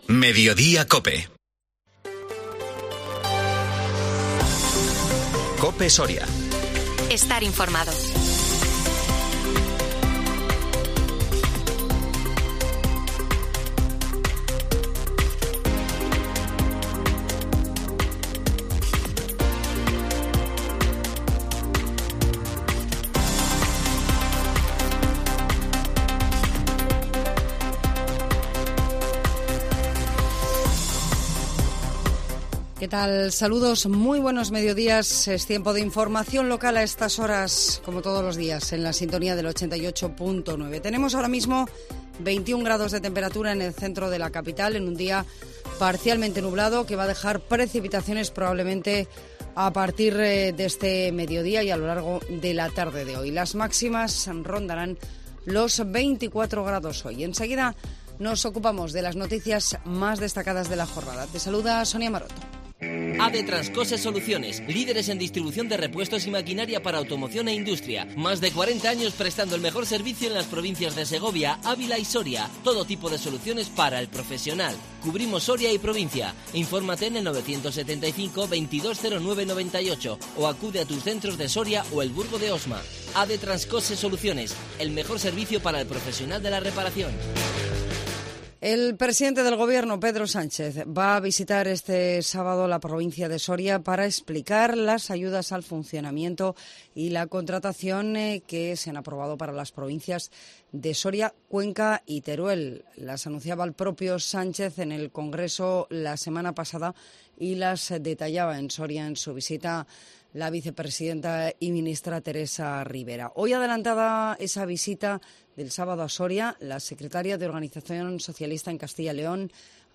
INFORMATIVO MEDIODÍA COPE SORIA 19 OCTUBRE 2022